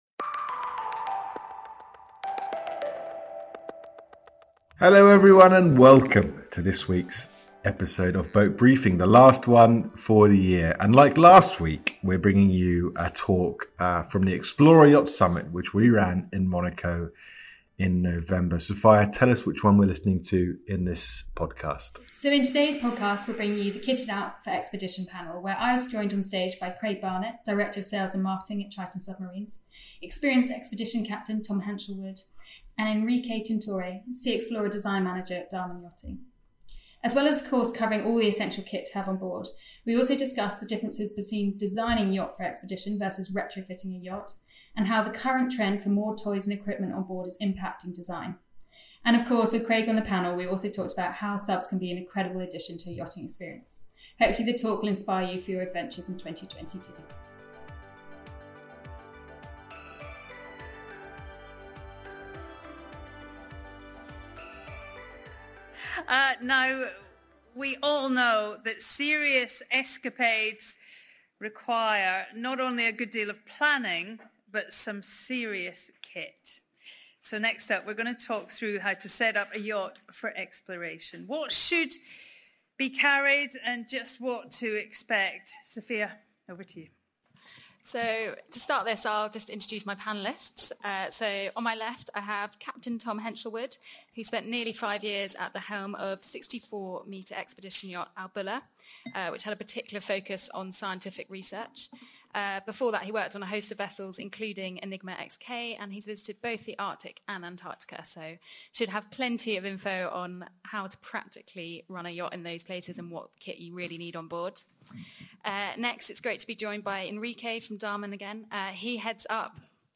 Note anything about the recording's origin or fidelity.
With the team still on holiday in this week’s podcast we bring you the “Kitted out for adventure” panel from the Explorer Yacht Summit.